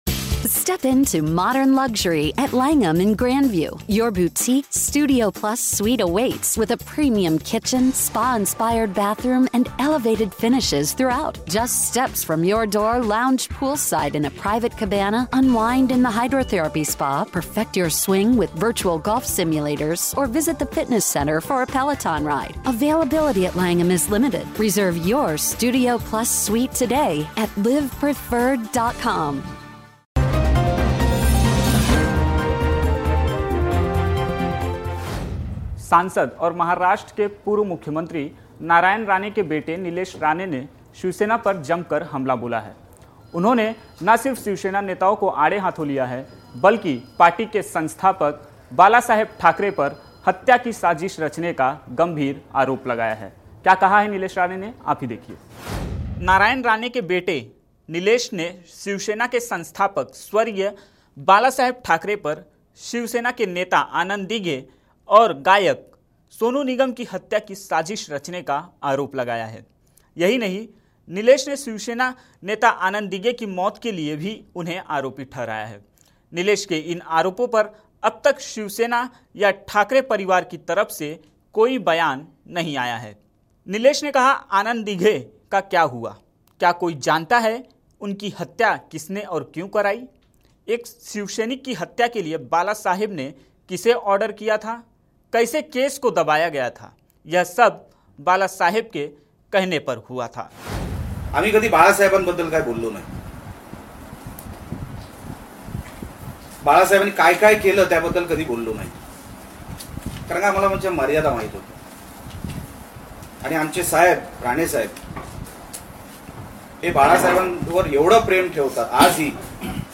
न्यूज़ रिपोर्ट - News Report Hindi / गायक सोनू निगम की हत्या करवाना चाहते थे बाल ठाकरे- पूर्व सांसद नीलेश राणे का आरोप